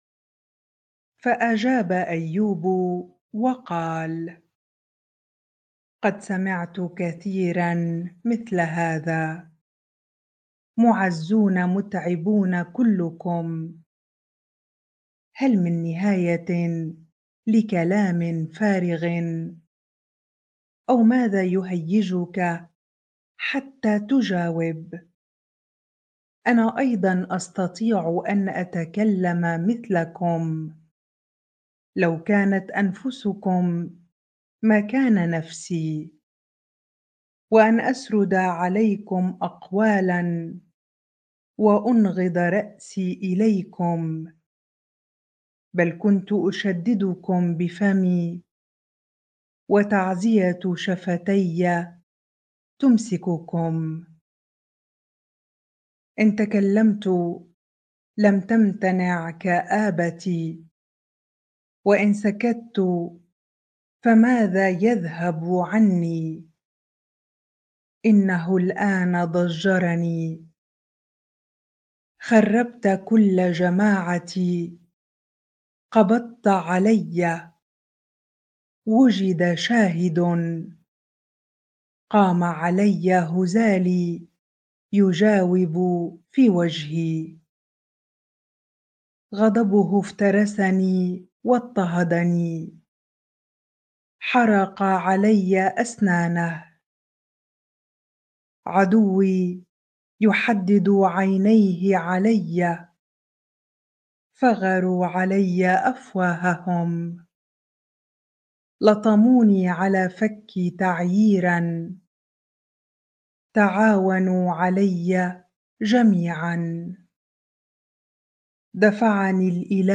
bible-reading-Job 16 ar